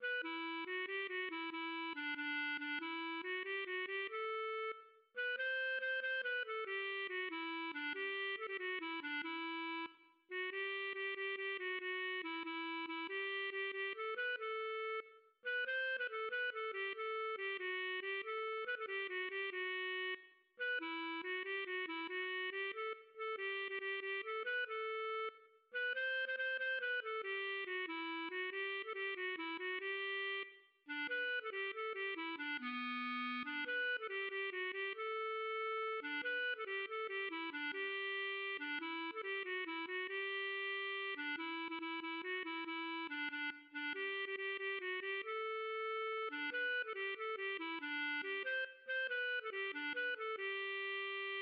\set Staff.midiInstrument="clarinet"